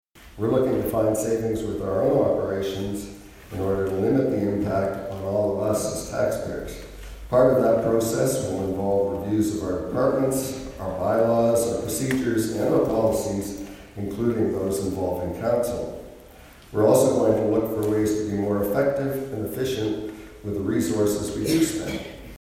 Ferguson addressed that in his speech as well, noting the County is no different than other municipalities in the province as they are also being asked to do more with less.